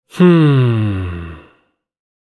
Hmmm Sound Effect
Hmmm-sound-effect.mp3